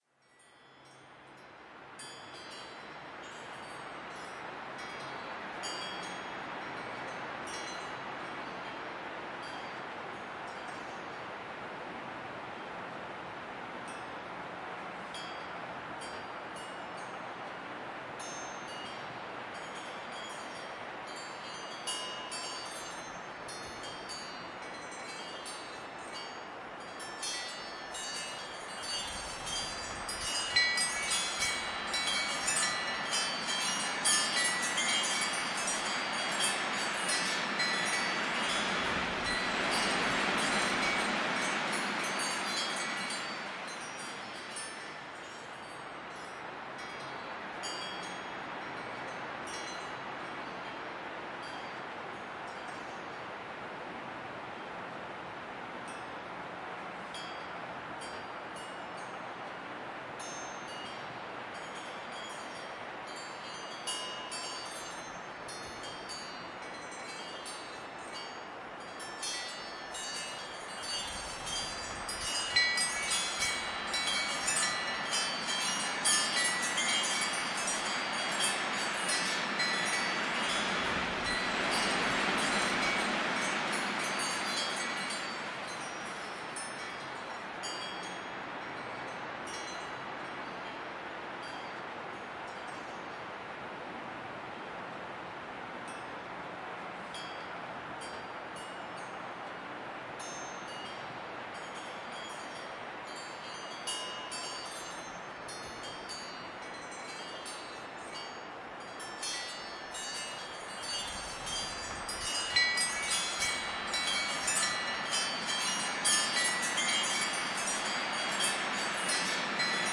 大风天里的风铃
描述：在刮风的日子里，有4套由银器制成的风铃。使用Zoom H4录制立体声，使用低切滤波器录制EQ'd，循环4倍以延长长度。
标签： 风铃 大风 阵风 H4 风铃 风铃
声道立体声